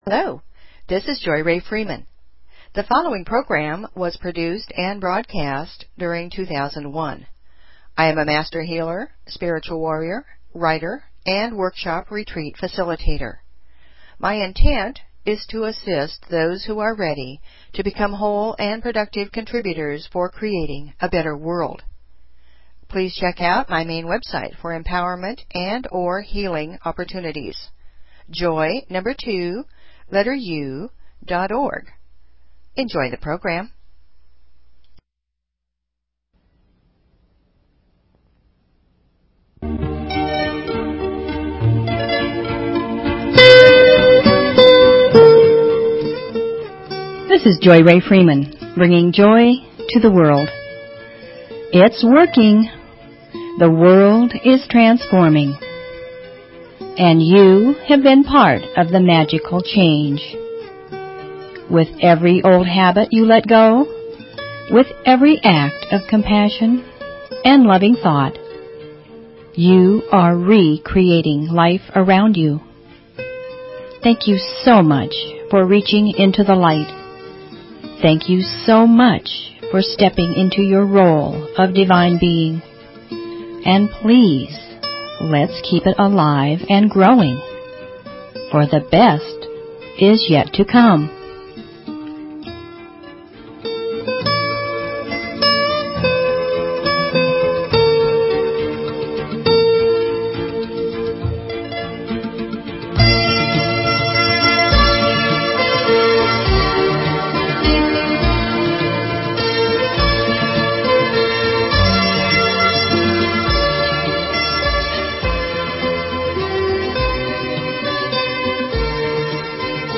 Talk Show Episode, Audio Podcast, Joy_To_The_World and Courtesy of BBS Radio on , show guests , about , categorized as
Music, poetry, affirmations, stories, inspiration . . .